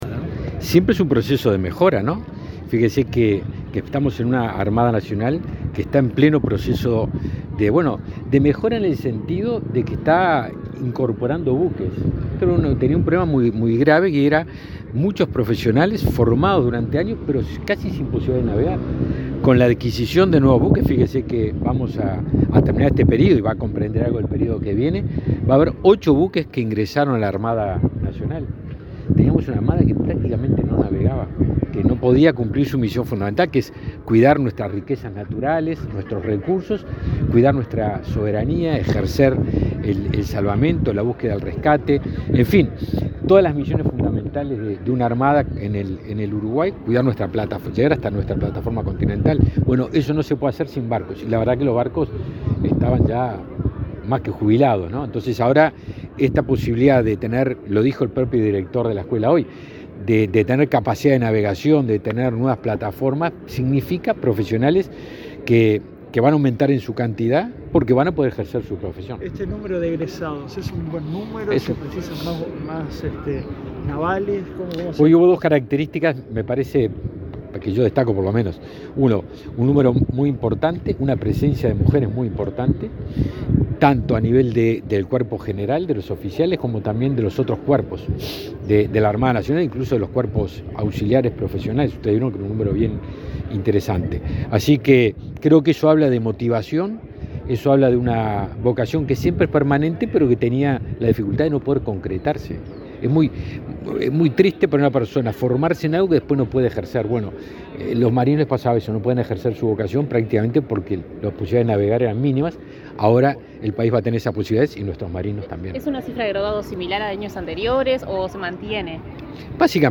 Declaraciones del ministro de Defensa Nacional, Javier García
El ministro de Defensa Nacional, Javier García, dialogó con la prensa, luego de participar del acto de fin de curso de la Escuela Naval.